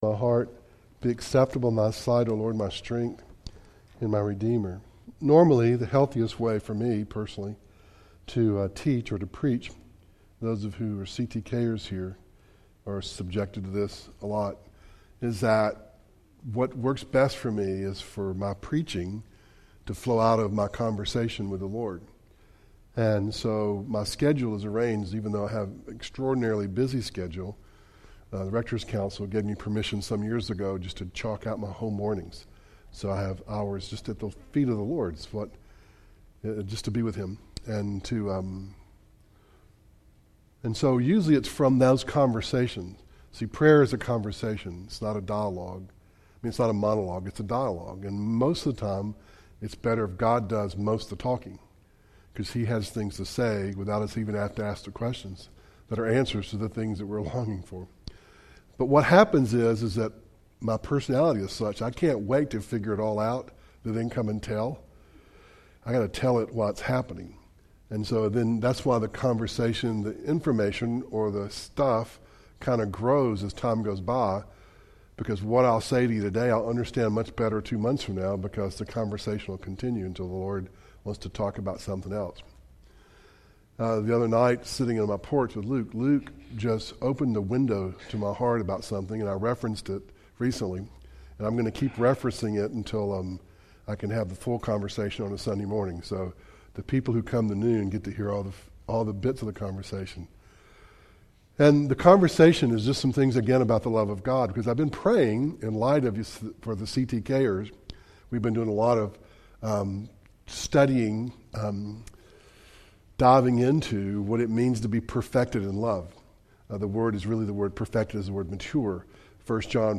Luke 19:1-10 Service Type: Devotional